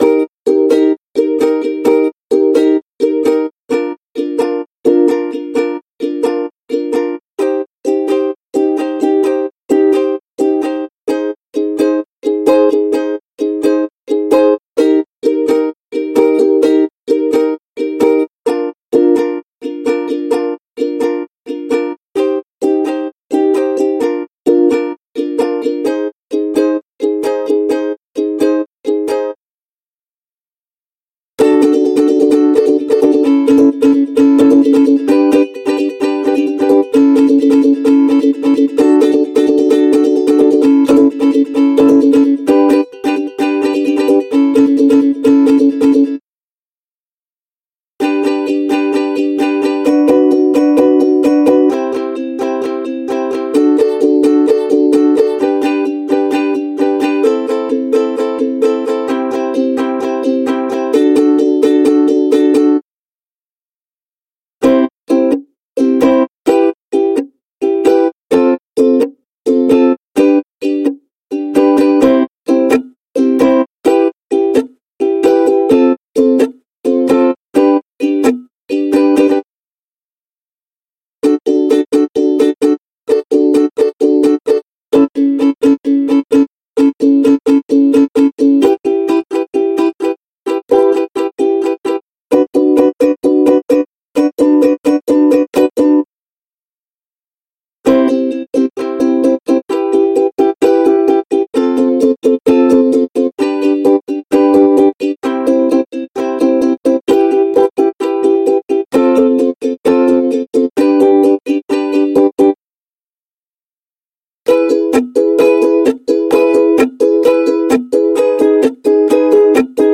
Укулеле звуки для видео: серия с укулеле